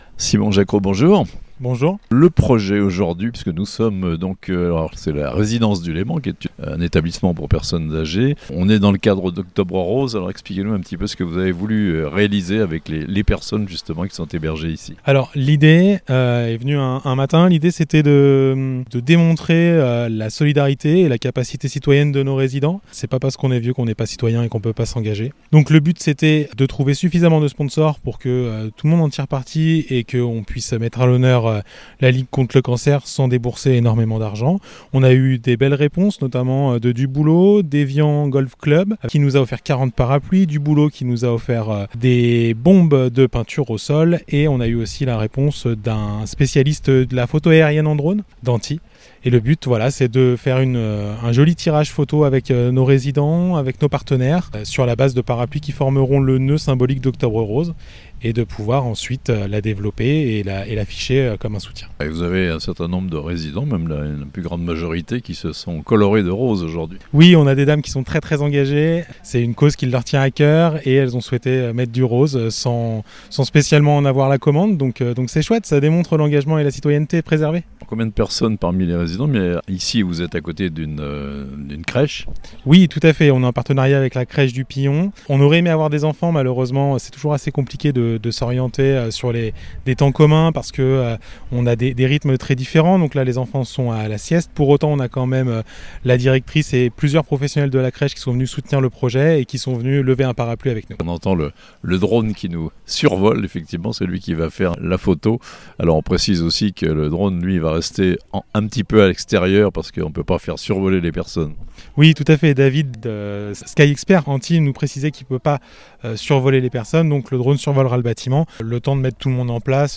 Octobre rose vu du ciel avec les résidents d'un EHPAD de Thonon (interview)